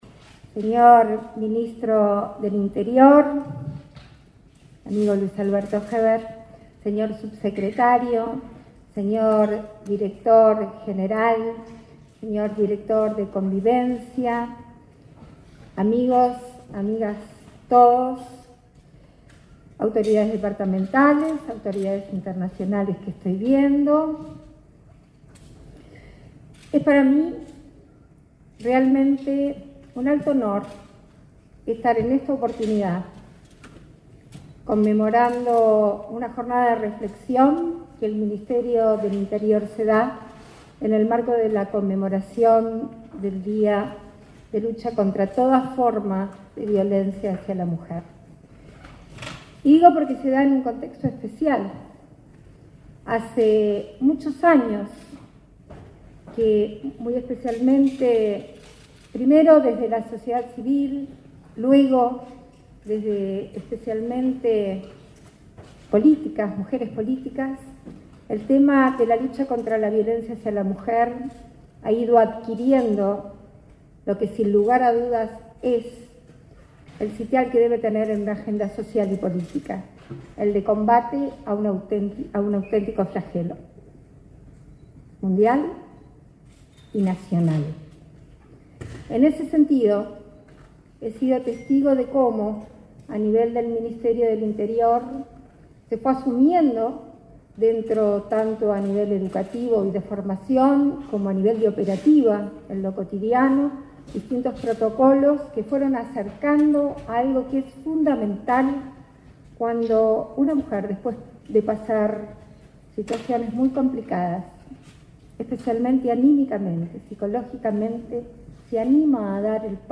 Conferencia de prensa sobre avances para erradicar la violencia doméstica y de género
Conferencia de prensa sobre avances para erradicar la violencia doméstica y de género 29/11/2021 Compartir Facebook X Copiar enlace WhatsApp LinkedIn Entre las actividades por el Día Internacional de la Eliminación de la Violencia contra la Mujer, este 29 de noviembre, el ministro del Interior, Luis Alberto Heber, sintetizó los avances de la cartera que dirige respecto a la temática. Participaron la vicepresidenta de la República, Beatriz Argimón, y la directora nacional de Políticas de Género, Angelina Ferreira.